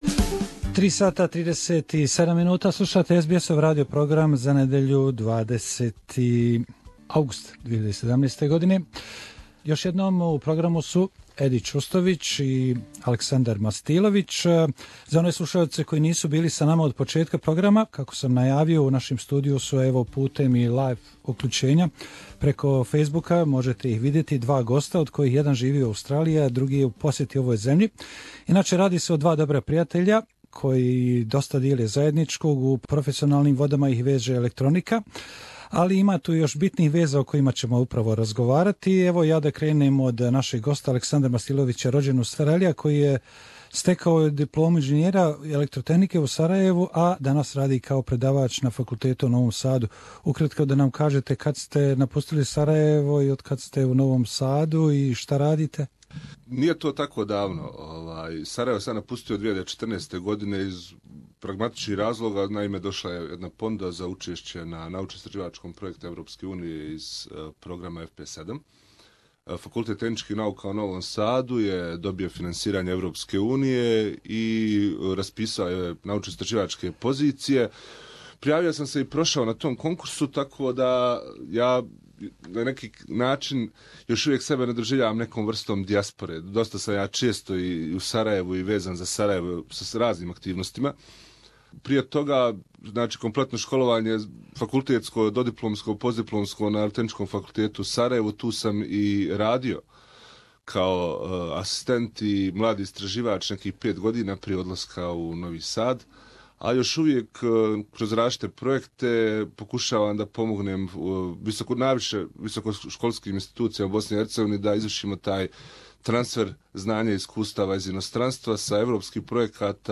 Interview - two guests in our studio